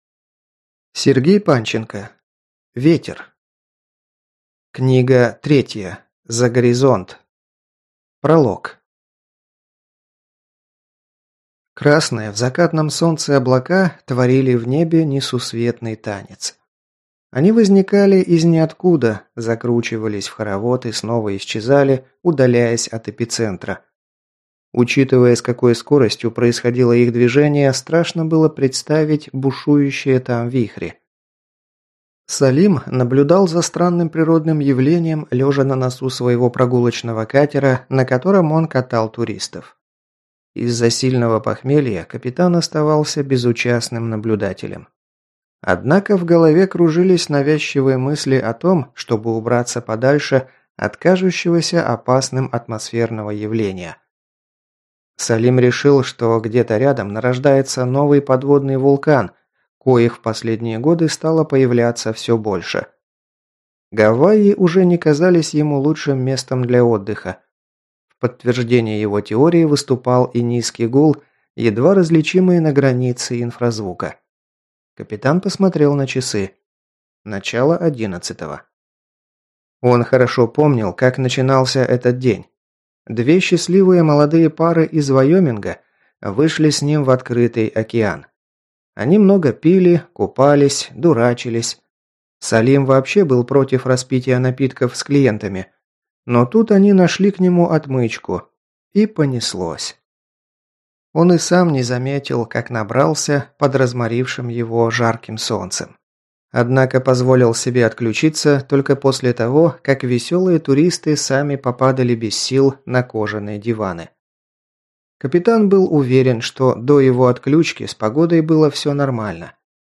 Аудиокнига Ветер. Книга 3. За горизонт | Библиотека аудиокниг